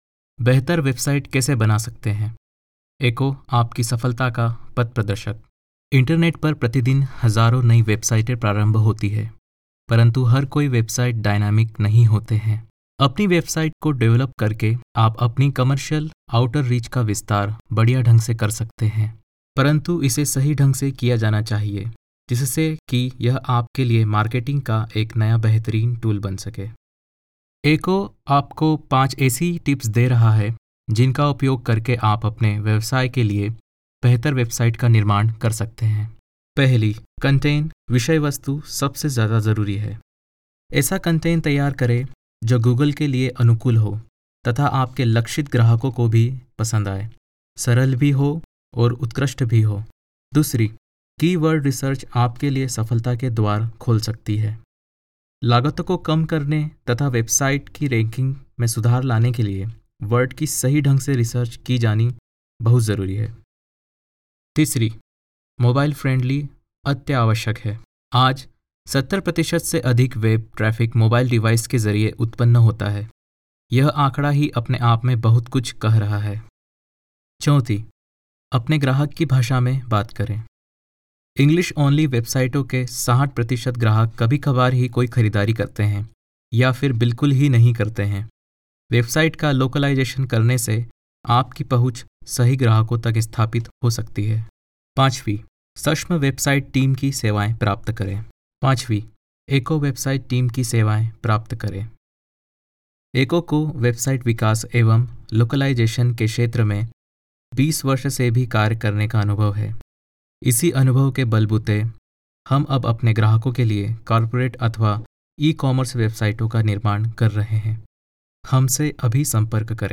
Voiceover Artists
EQHO provides multi-language solutions from its in-house recording facilities
Hindi Male
NARRATION